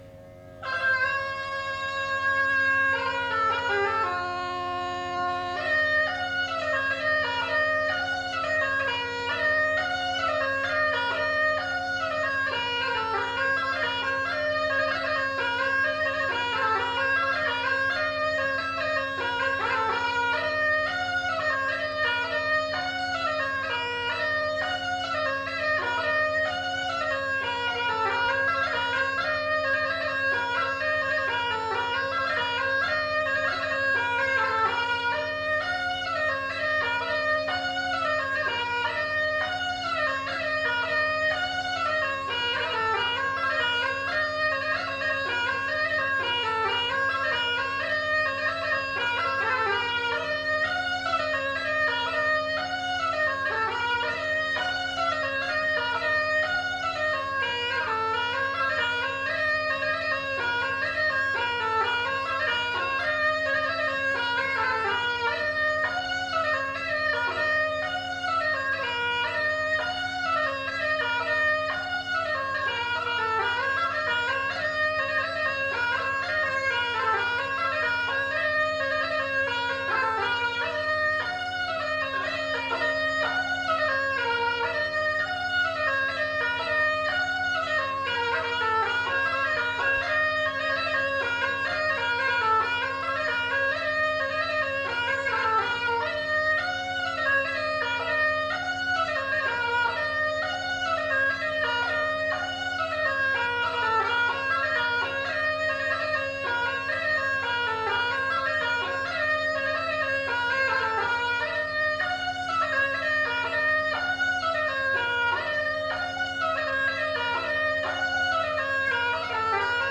Aire culturelle : Cabardès
Genre : morceau instrumental
Instrument de musique : craba
Danse : polka piquée